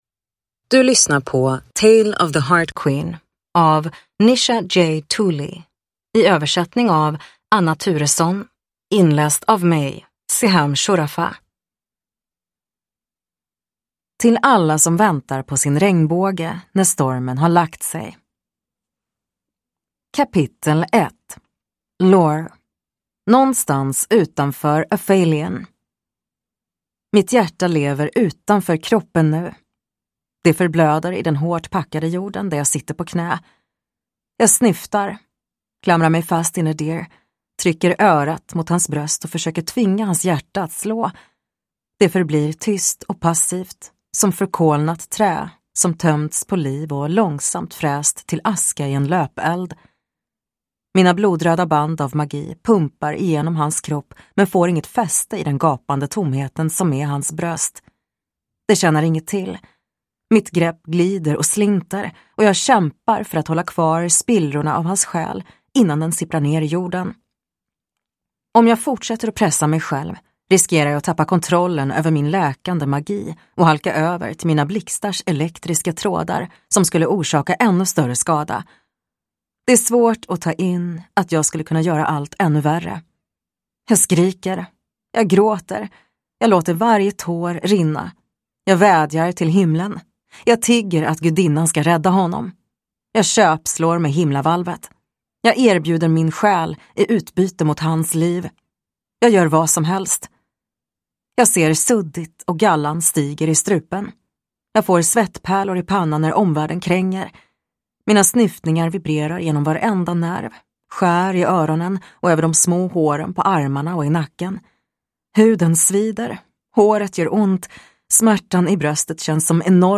Tale of the Heart Queen (svensk utgåva) – Ljudbok